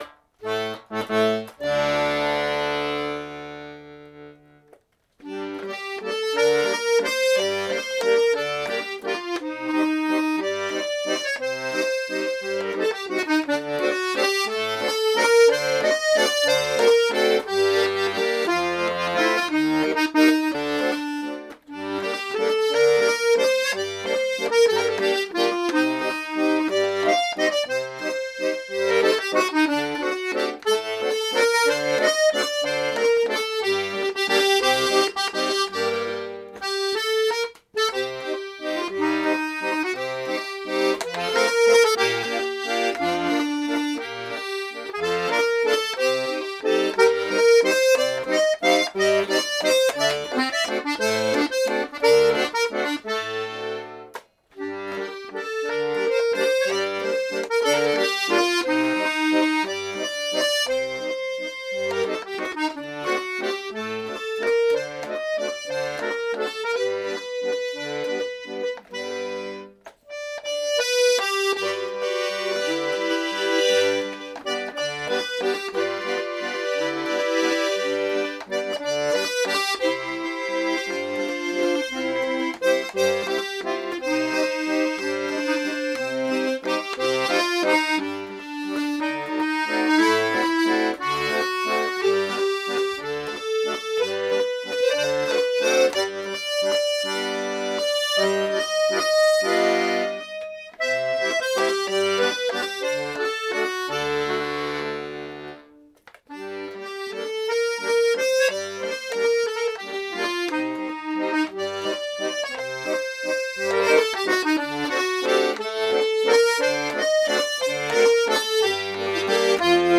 erstaunlich klangvoll, kräftiges Tremolo
444 Hz / 20 cent